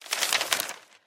open_map.mp3